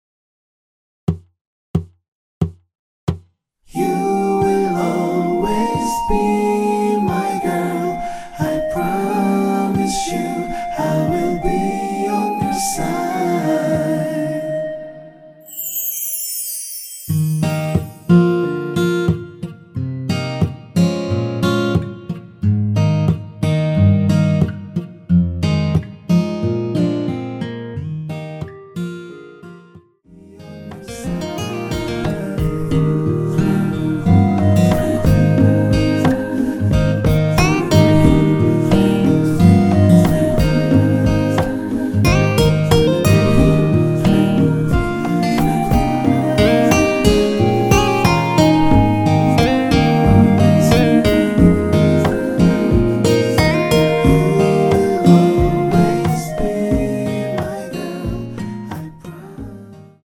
노래 들어가기 쉽게 전주 1마디 넣었습니다.(미리듣기 확인)
원키에서(-1)내린 멜로디와 코러스 포함된 MR입니다.
Eb
앞부분30초, 뒷부분30초씩 편집해서 올려 드리고 있습니다.